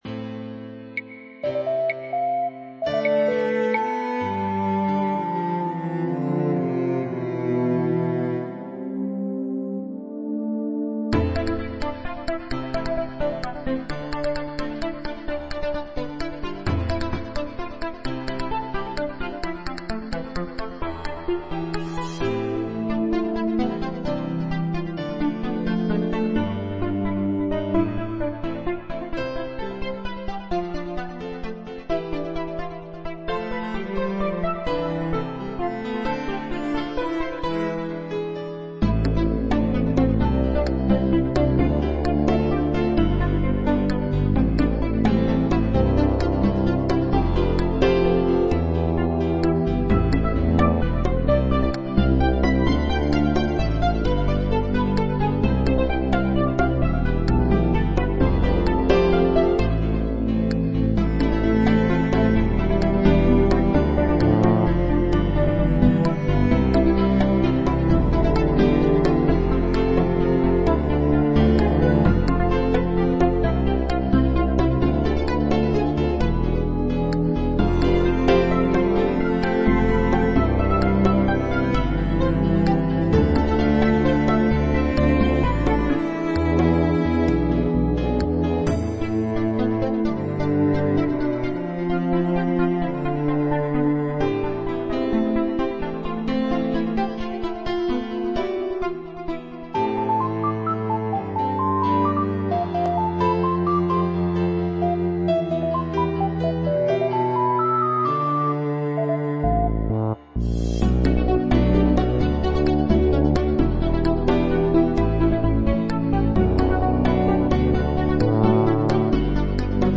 Melancholic Soundtrack music with Synth Lead